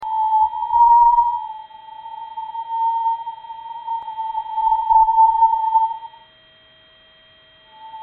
A wind of fast moving particles blows out from our Sun, and although space transmits sound poorly, particle impact and variable-field data from NASA's near-Sun Parker Solar Probe is being translated into sound.
psp_langmuir_waves.mp3